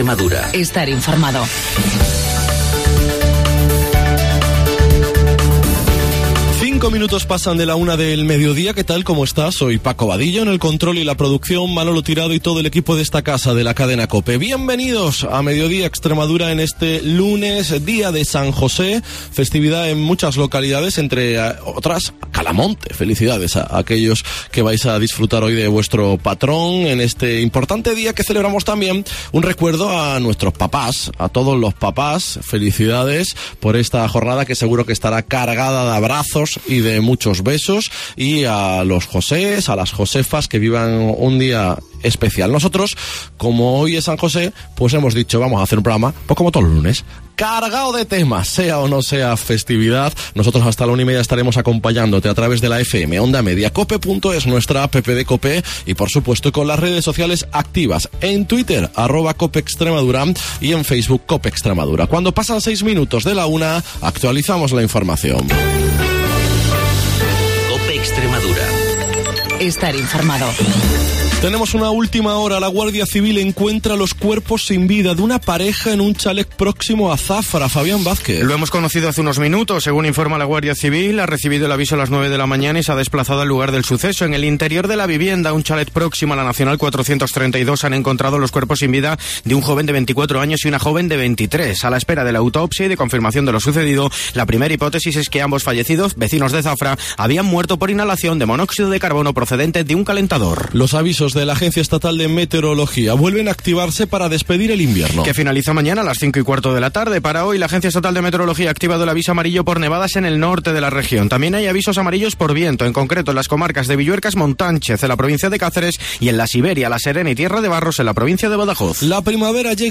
El programa líder de la radio extremeña